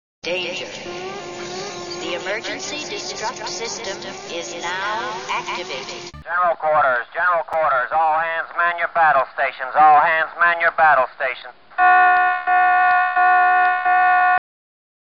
emergency-destruct-and-battle-stations.wav